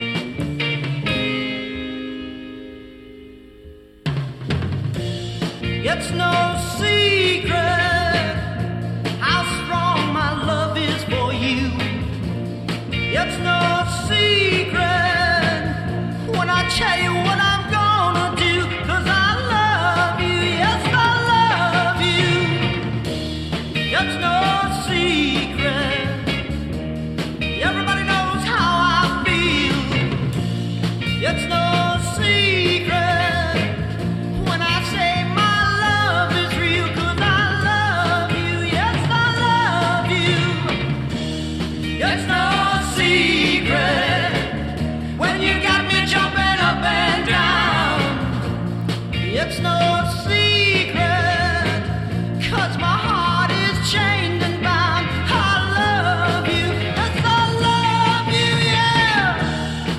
Рок
"кислотная" гитара и глубокий, потусторонний вокал